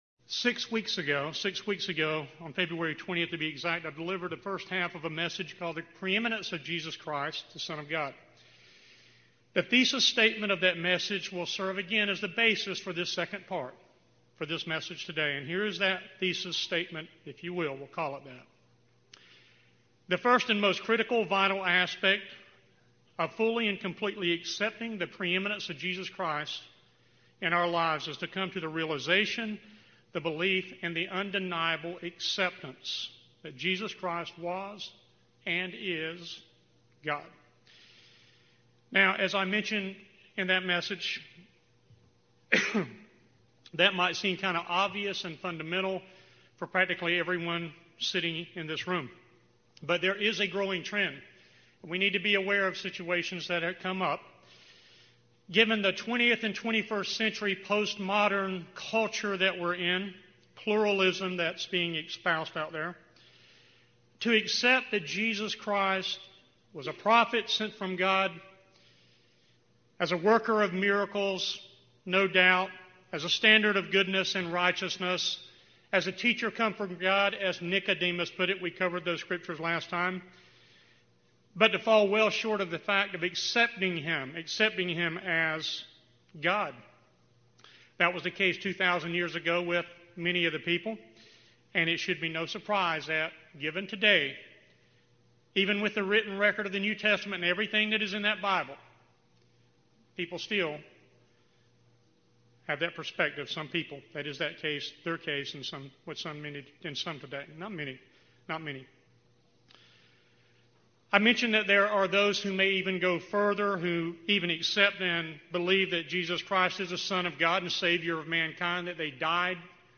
This Part 2 sermon continues laying the scriptural foundation for the recognition, understanding,